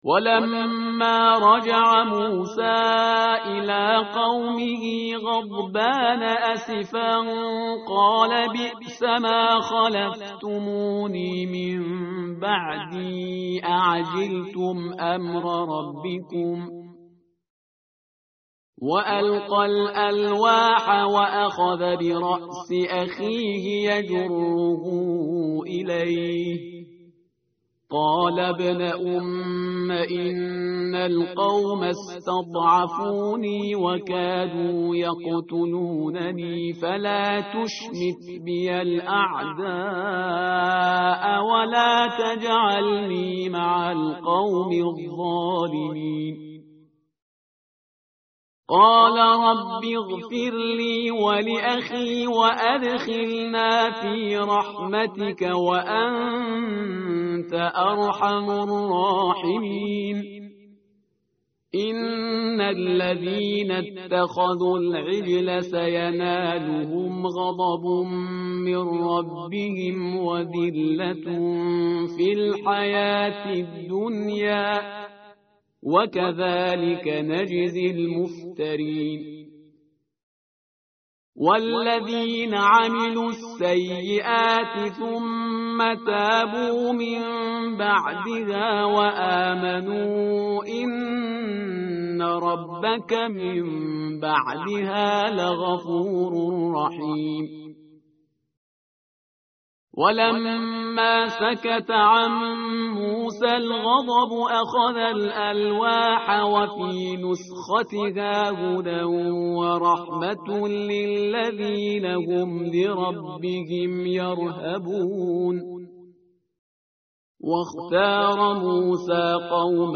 متن قرآن همراه باتلاوت قرآن و ترجمه
tartil_parhizgar_page_169.mp3